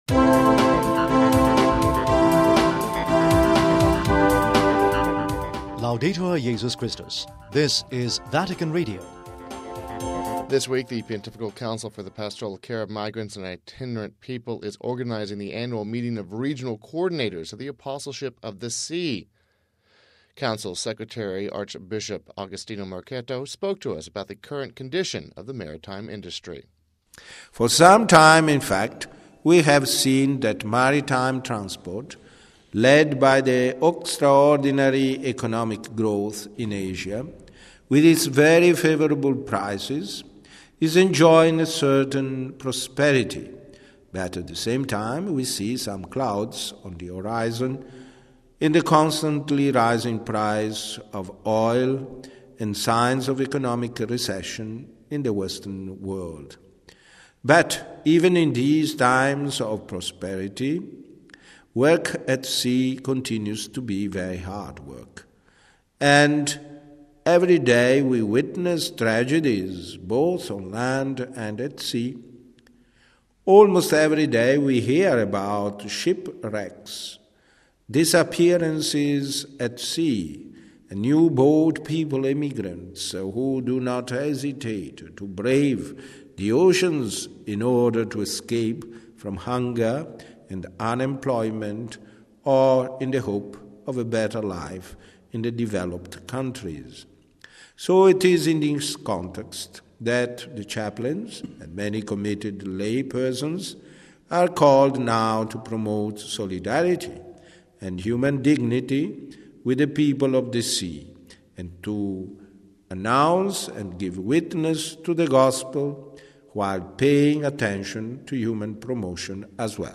This week the Pontifical Council for the Pastoral Care of Migrants and Itinerant People is organizing the annual meeting of Regional Coordinators of the Apostleship of the Sea who represent the different regions of the maritime world. The Secretary of the Council, Archbishop Agostino Marchetto spoke about the current condition of the maritime industry...